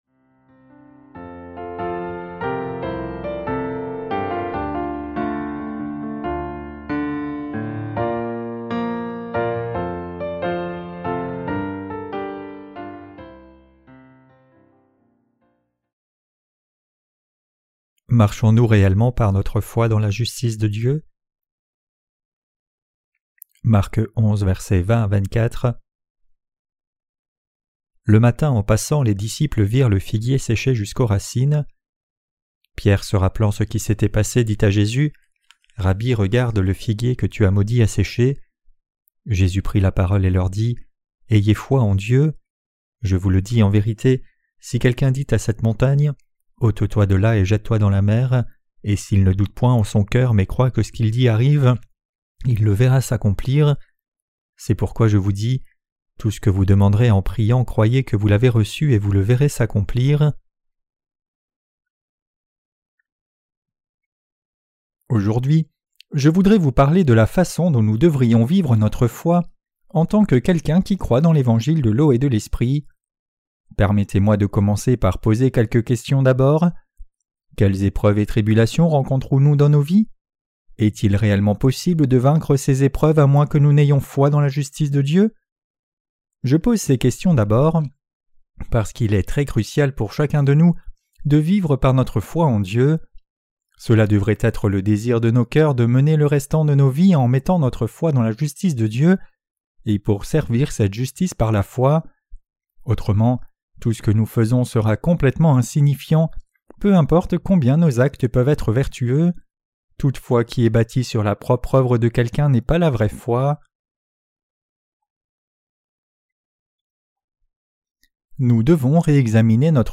Sermons sur l’Evangile de Marc (Ⅲ) - LA BÉNÉDICTION DE LA FOI REÇUE AVEC LE CŒUR 3.